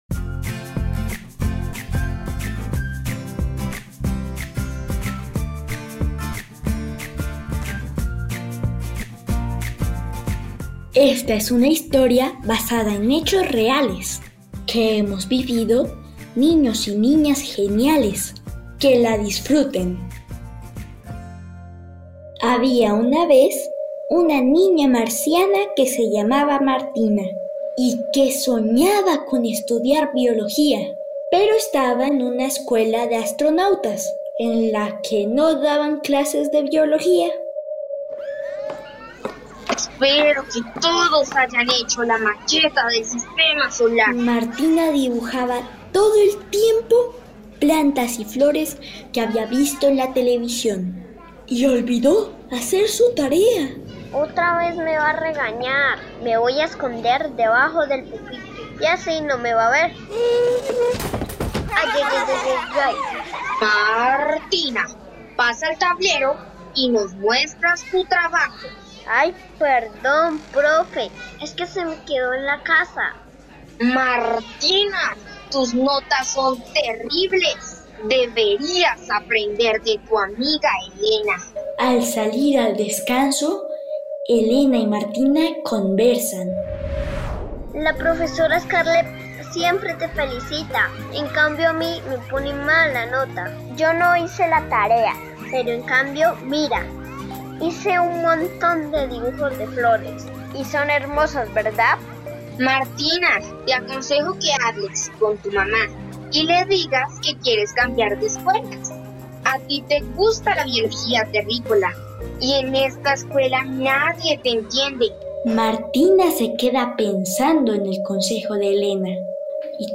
Radiocuento - La escuela soñada - Pódcast Geniales para niños | RTVCPlay
GENIA_T01_CUENTOS_EN_RADIOTEATRO_C01_ALTA.mp3